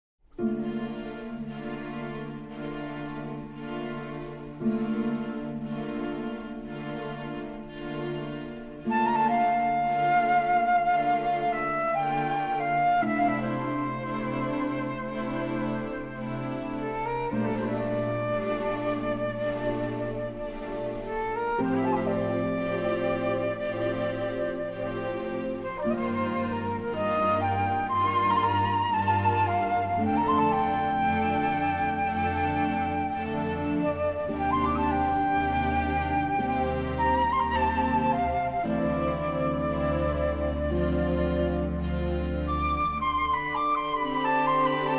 Utilizing exotic percussion and a talented flute soloist
was recorded by a non-union orchestra in Toronto